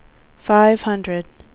WindowsXP / enduser / speech / tts / prompts / voices / sw / pcm8k / number_124.wav